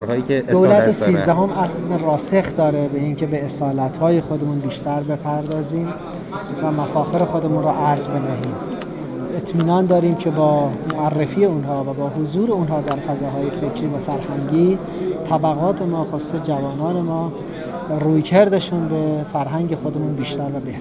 شالویی در گفت‌وگو با ایکنا بیان کرد:
به مناسبت بازگشایی نمایشگاه «پنج گنج» در موزه هنرهای معاصر تهران، محمود شالویی، دستیار وزیر فرهنگ و ارشاد اسلامی در گفت‌وگو با خبرنگار ایکنا بیان کرد: نمایشگاه «پنج گنج» یک روایت مفهومی است از موضوعاتی که با حکایت‌ها و قصه‌های نظامی در هم آمیخته،‌ اشعار تطبیق داده شده و یک نگاه نو و جدیدی افکنده شده است.